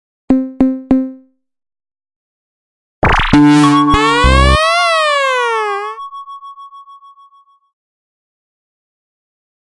这是一个奇怪的电子效应loog，是由Waldorf Attack VST Drum Synth创建的。
Tag: 回路 怪异 电子 ConstructionKit 舞蹈 120BPM 科幻 有节奏